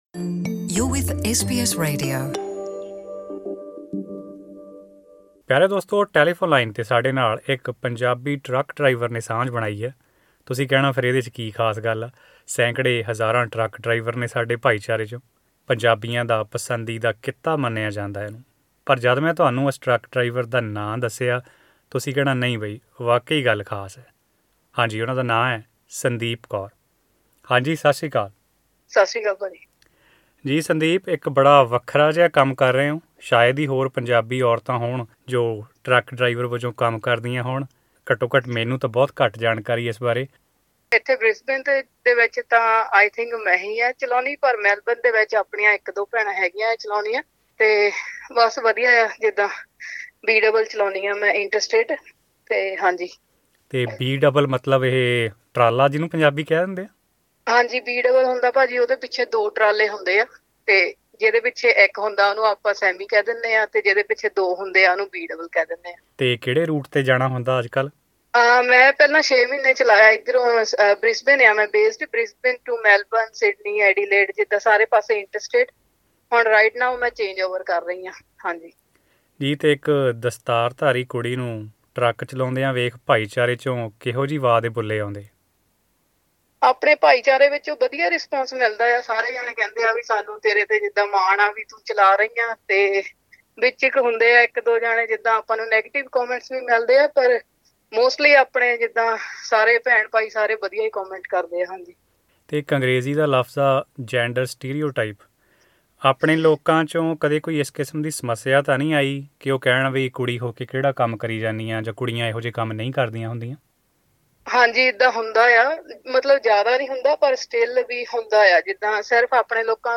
In an interview with SBS Punjabi she spoke about the advantages as well as challenges in the life of a heavy vehicle driver.